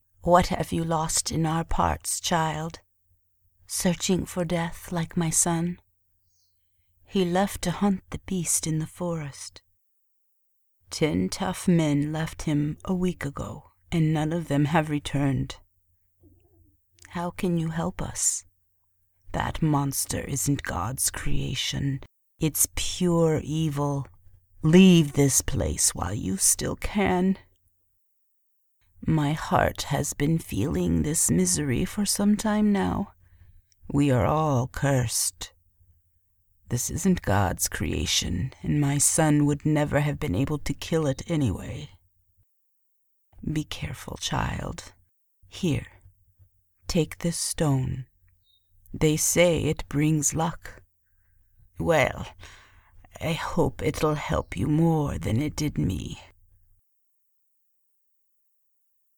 Female
English (North American)
Teenager (13-17), Adult (30-50)
Smooth, Clear, Warm.
Can do character voices, accents to enhance narrations or gaming projects.
Video Games